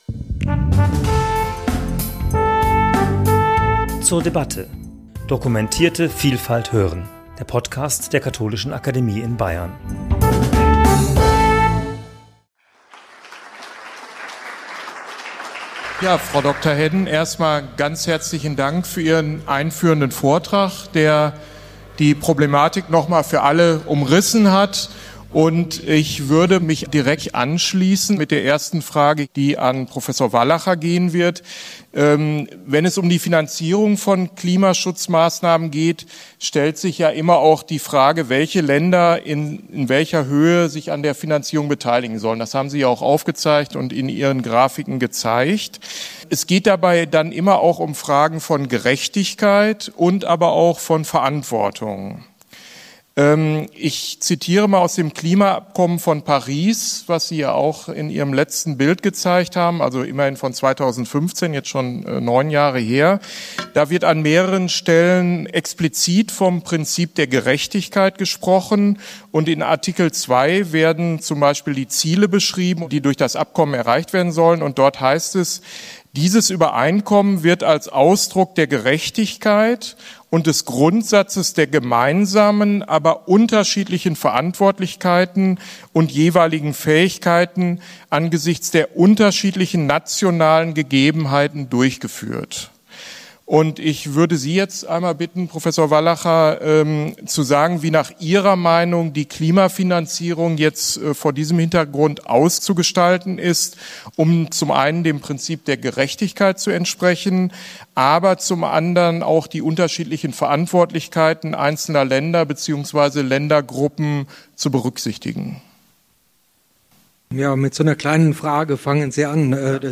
Podiumsdiskussion zum Thema 'Herausforderung Klimafinanzierung - Klimaschutz sucht Geldgeber' ~ zur debatte Podcast
Wie komplex und vielschichtig die Suche nach Geldgebern für Klimaschutzprojekte ist, zeigte das von der Katholischen Akademie in Bayern in Kooperation mit der Münchener Rück Stiftung durchgeführte Dialogforum im Rahmen des Münchner Klimaherbst 2024. Bei der Suche nach Geldgebern für den Klimaschutz stellt sich immer auch die Frage nach Fairness und Gerechtigkeit.